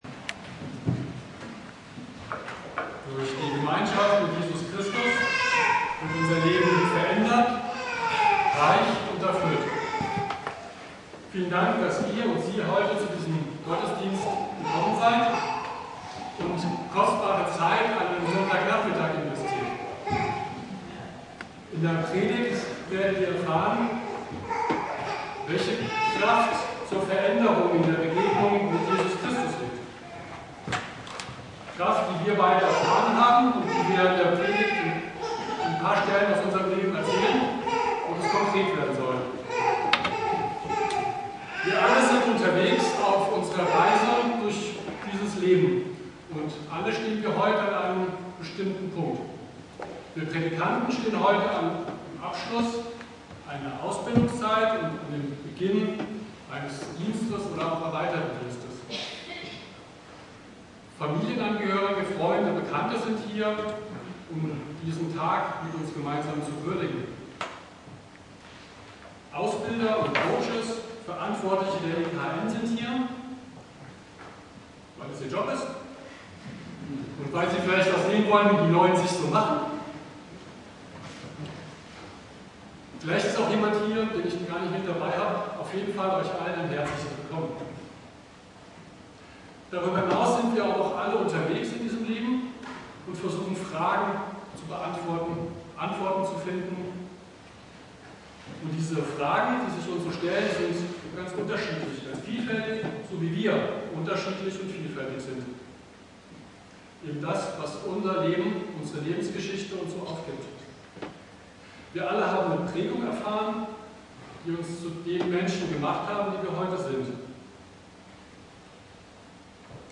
Predigt
Zachäus - Eine Ermutigungsgeschichte Inhalt der Predigt: Prädikanten-Bevollmächtigungs-Predigt im Festgottesdienst in der Ev.